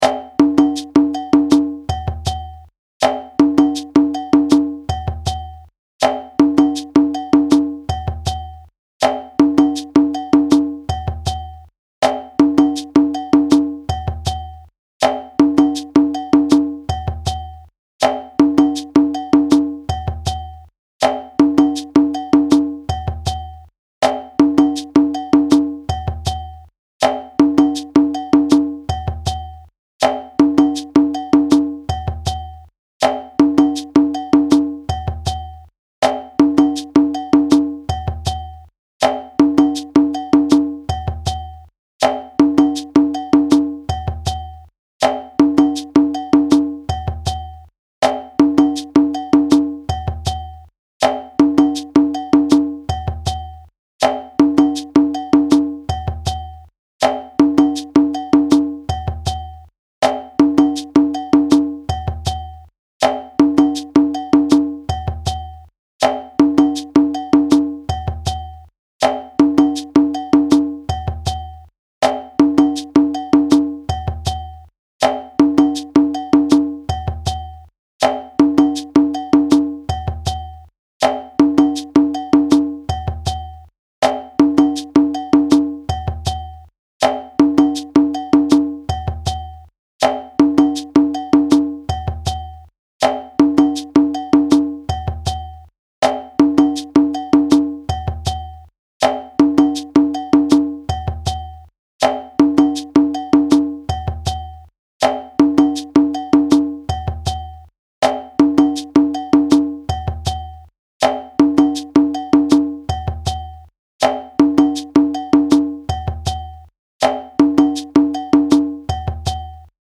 A typical break phrase commonly used in djembe music to start or stop a rhythm or transition into another section.
audio (with shekeré  & bell)
Djembe-Break-2-4_4-hh.mp3